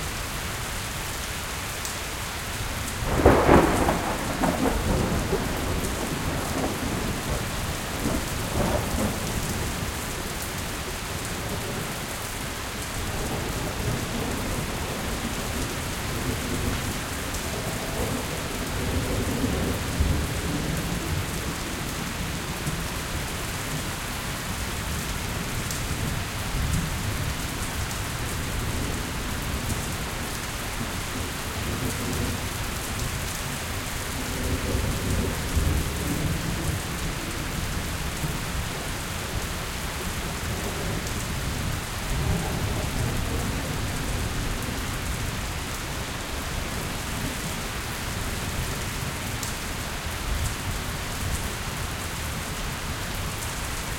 Rain Loop.ogg